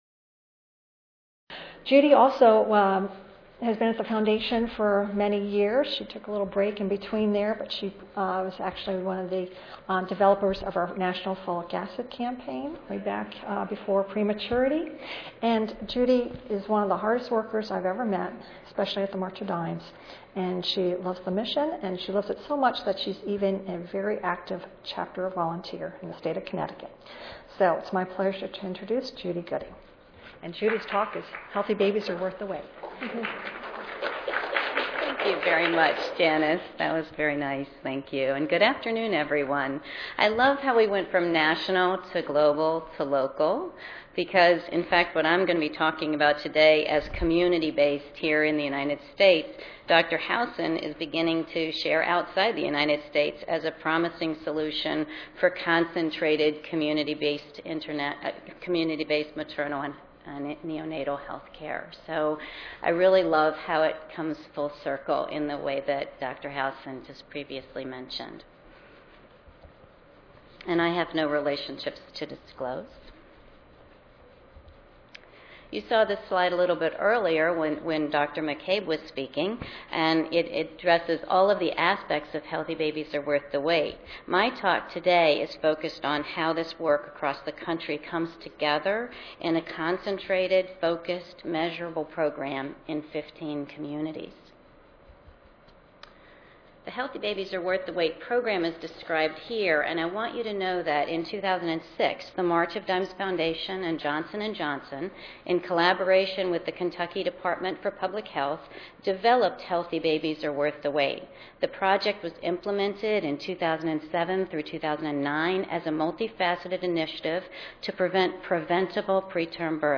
The presentations in this session will summarize key accomplishments and describe the activities and future plans of the Prematurity Campaign, both in the United States and abroad.